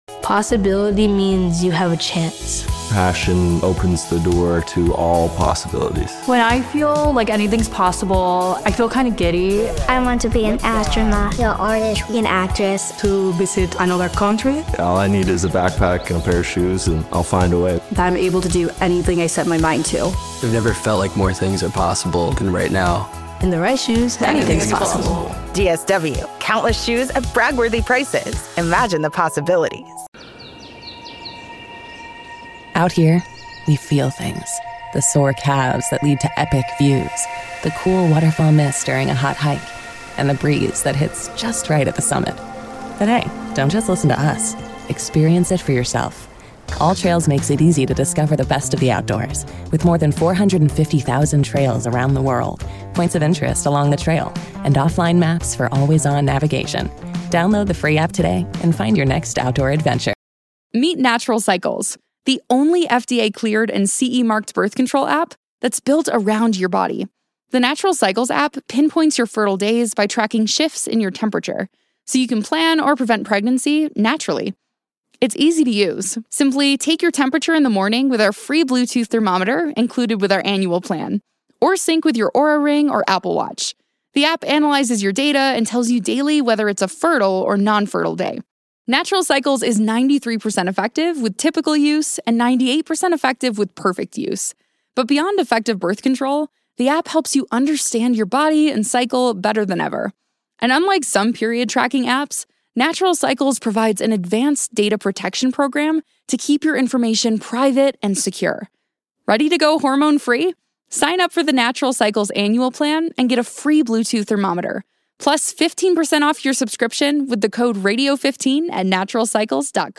Callers give their predictions for what the Commanders will be in the 2025 season after making the NFC Championship game last season.